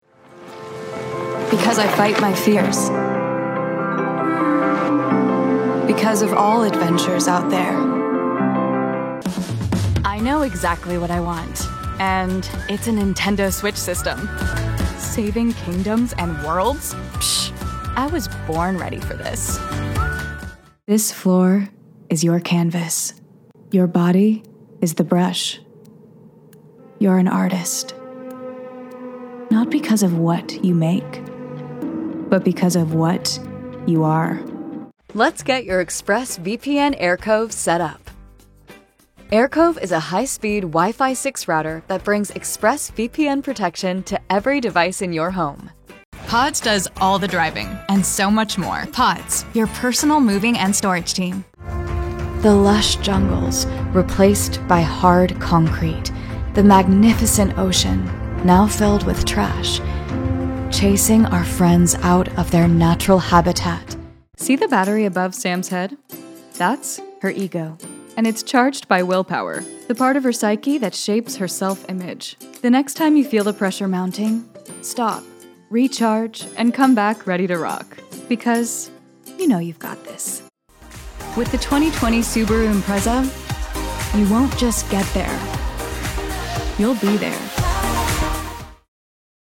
Voiceover Reel
English (North American), English (British and European)